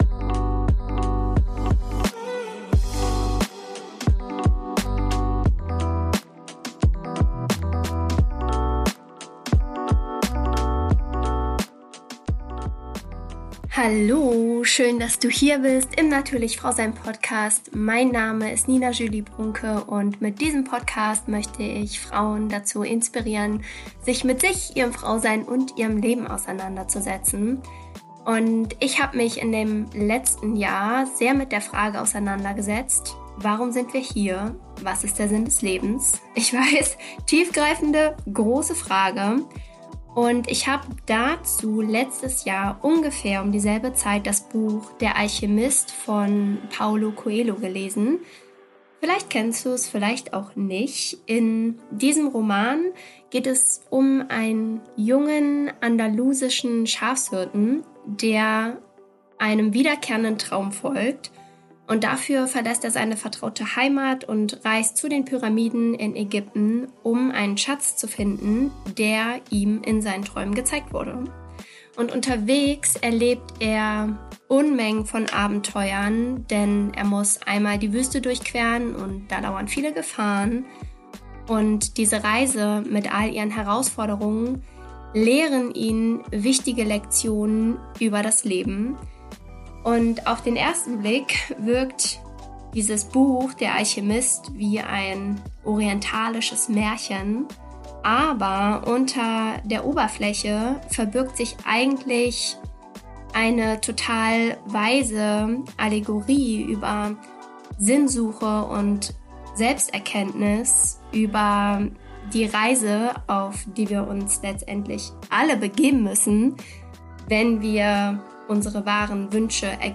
Heute möchte ich dir das Vorwort von diesem Roman als Geschichte vorlesen.